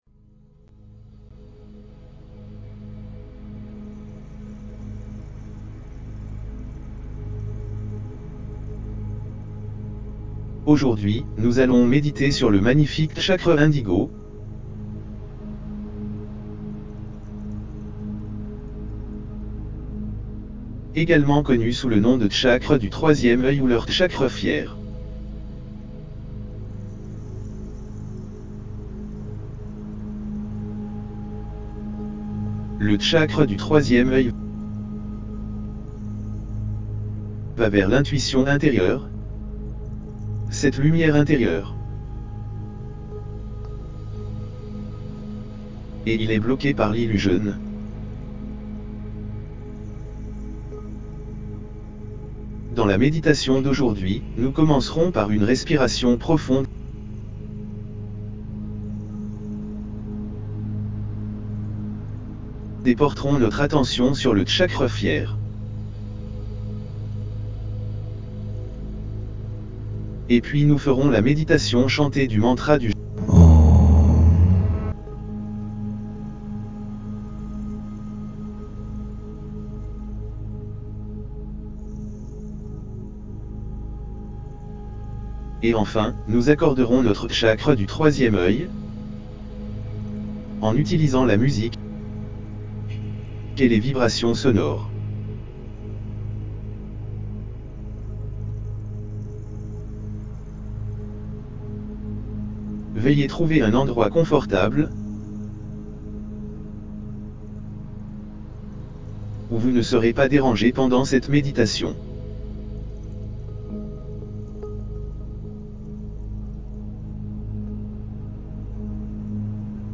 6ThirdEyeChakraHealingGuidedMeditationFR.mp3